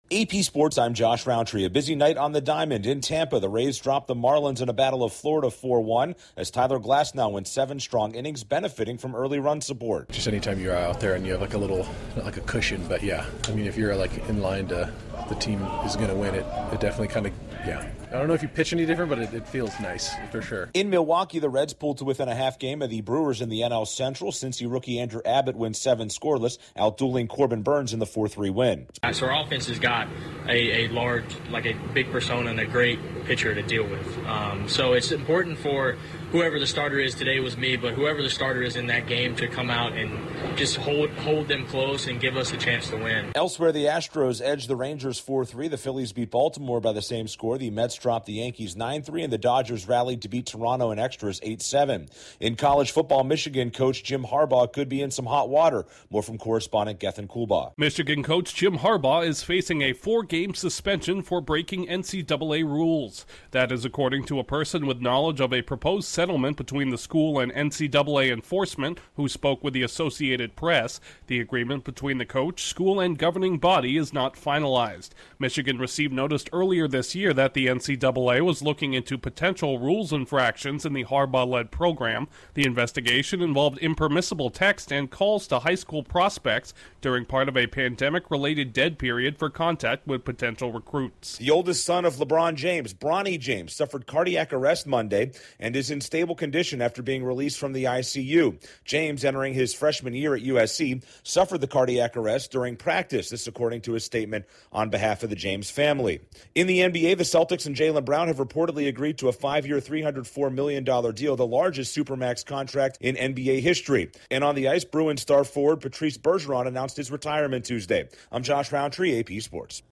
Headliner Embed Embed code See more options Share Facebook X Subscribe The Rays, Reds, Astros, Phillies, Mets and Dodgers pick up wins on the diamond, Michigan football coach Jim Harbaugh could be facing suspension, LeBron James’ son has a medical scare, the Celtics and Jaylen Brown are on the verge of a record deal, and Patrice Bergeron retires from the NHL. Correspondent